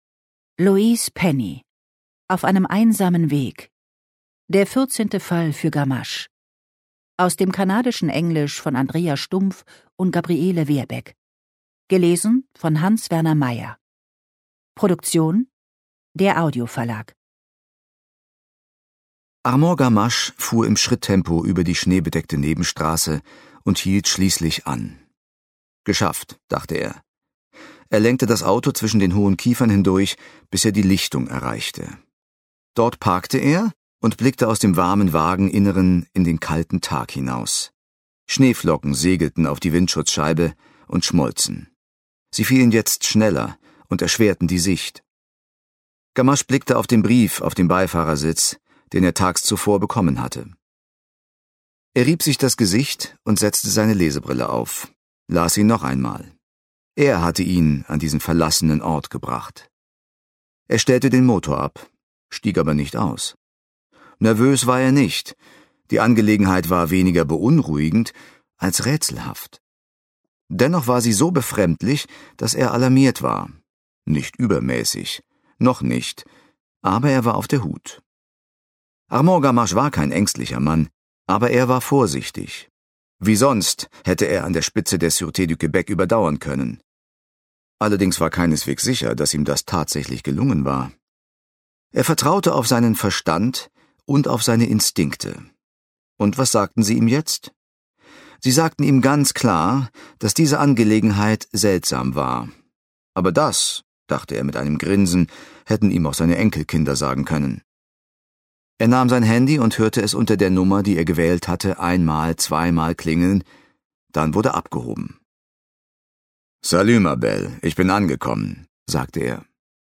Ungekürzte Lesung mit Hans-Werner Meyer (2 mp3-CDs)
Hans-Werner Meyer (Sprecher)